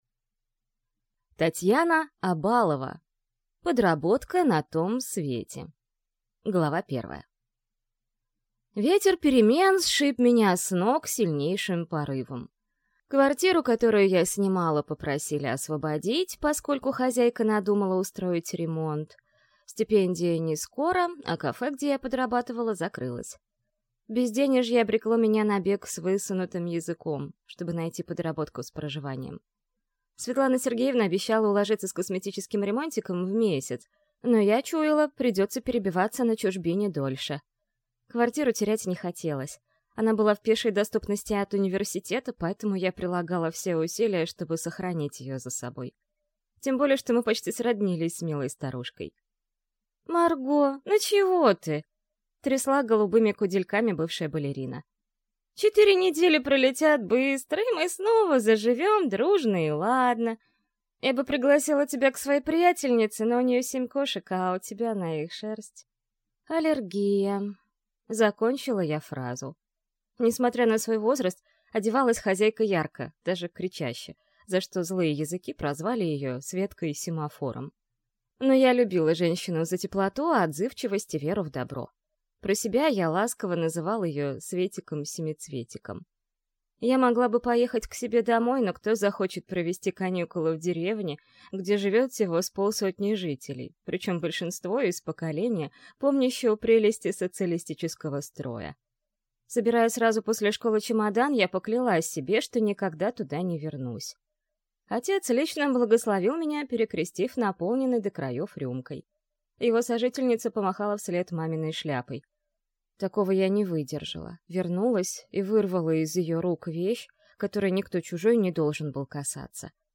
Аудиокнига Подработка на том свете | Библиотека аудиокниг